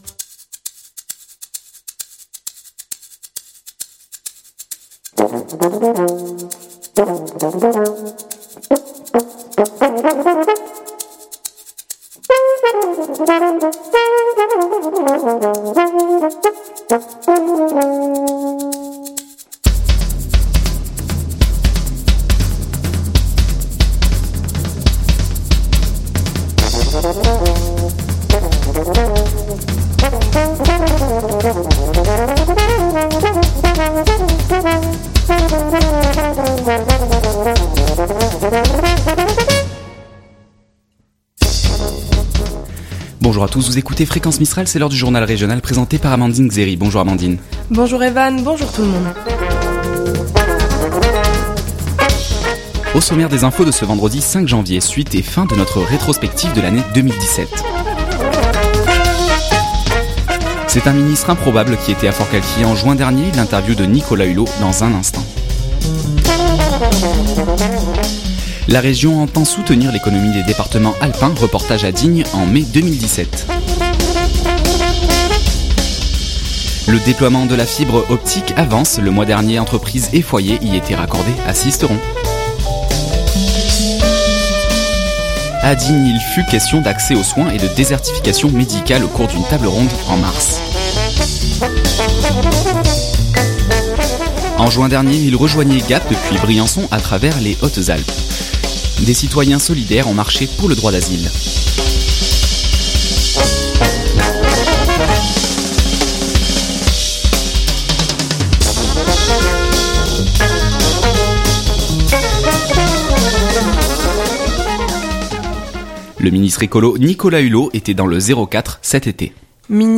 L’interview de Nicolas Hulot dans un instant.
Reportage à Digne en mai 2017.